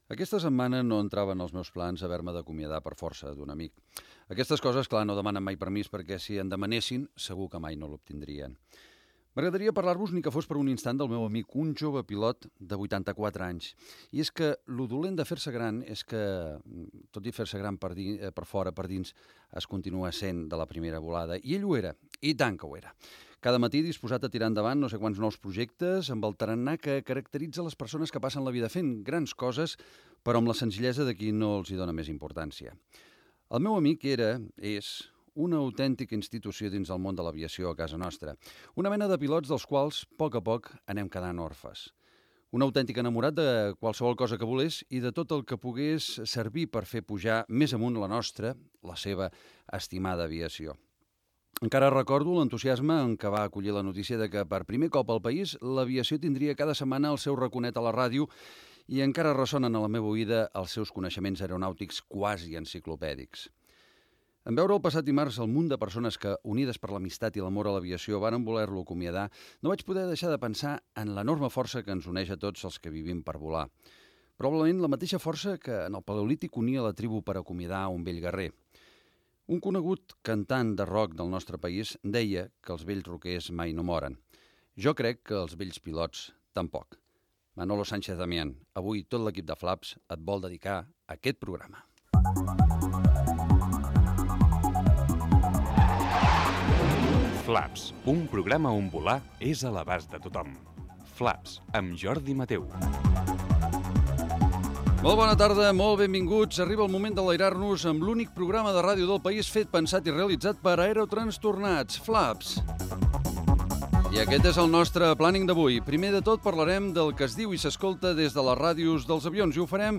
careta del programa, sumari de continguts, publicitat
Gènere radiofònic Divulgació